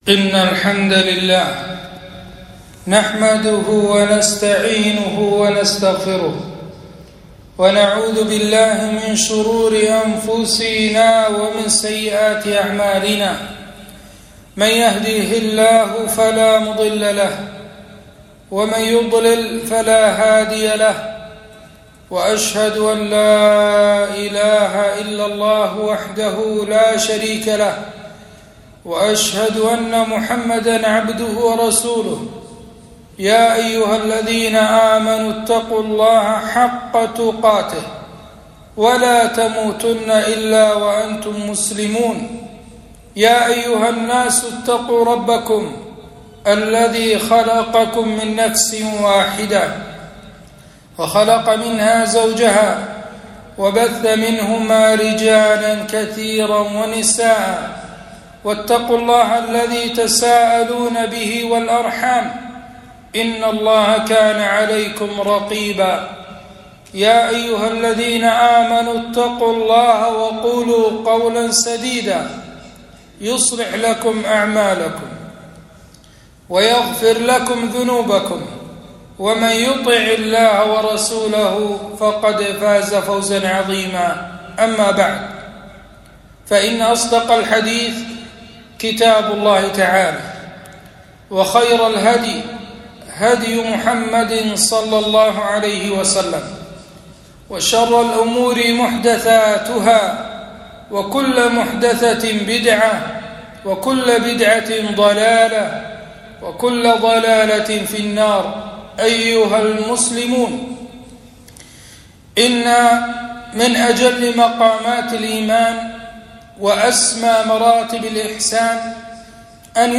خطبة - حسن الظن بالله